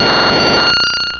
Cri de Rafflesia dans Pokémon Rubis et Saphir.
Cri_0045_RS.ogg